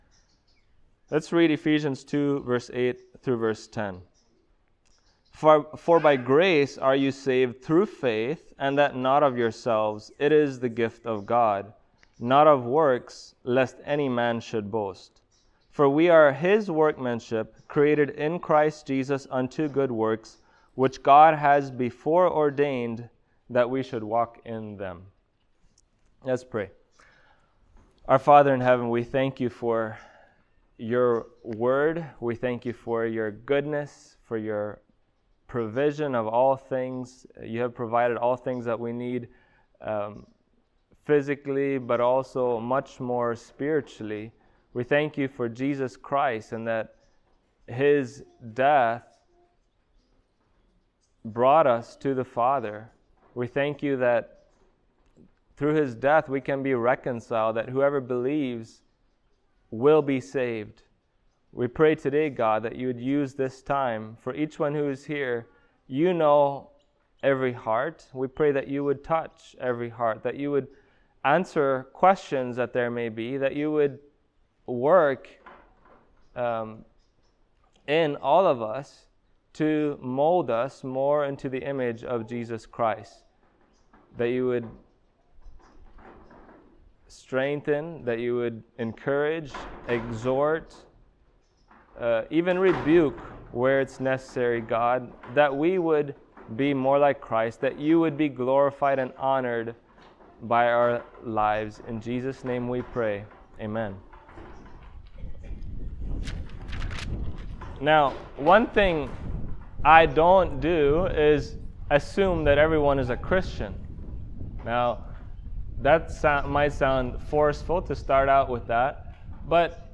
Sunday Moring